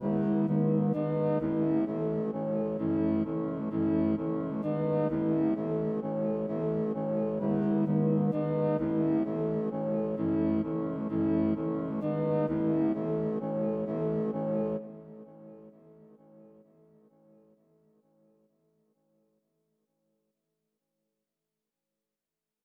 EN - Kanye (130 BPM).wav